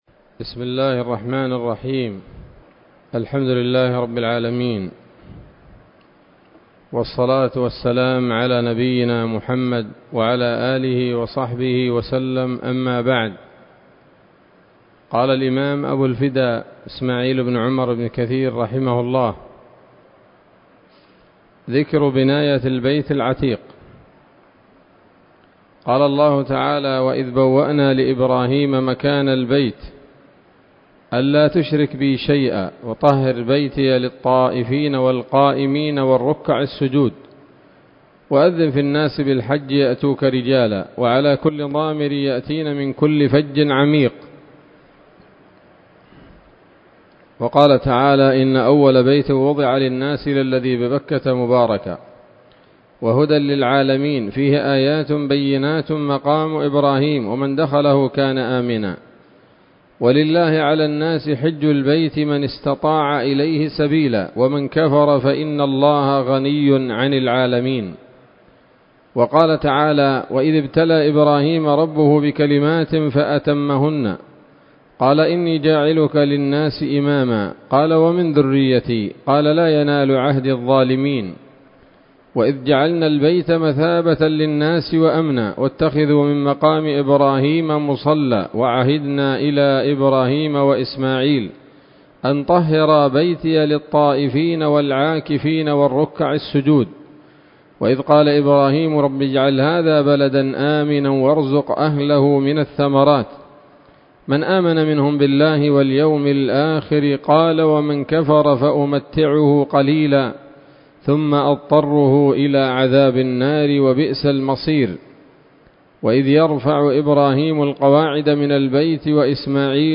الدرس الحادي والخمسون من قصص الأنبياء لابن كثير رحمه الله تعالى